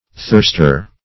Thirster \Thirst"er\, n.